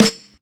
78_SNR.wav